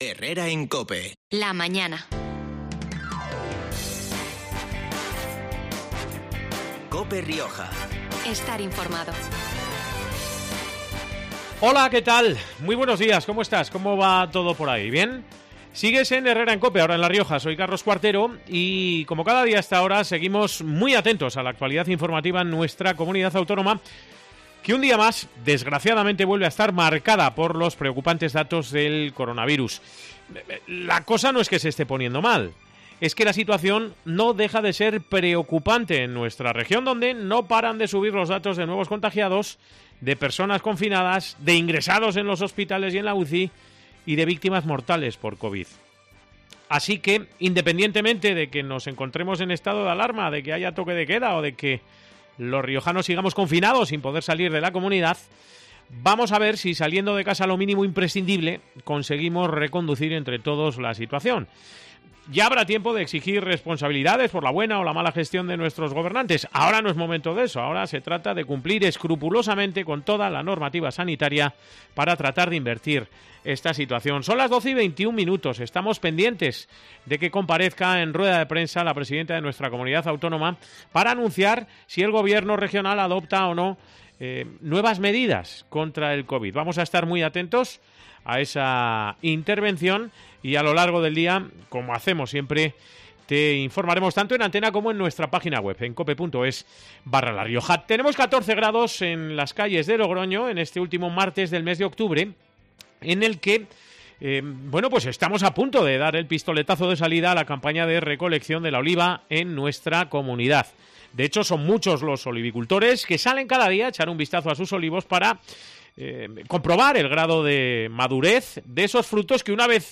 Con él hemos hablado este mediodía en COPE Rioja de esa campaña, de la excelente calidad de su producto y de su nueva página web.